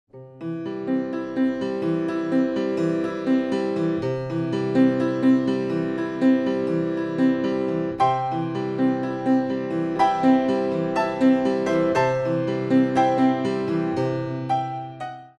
Adagio
for Bow
intro + 6 bars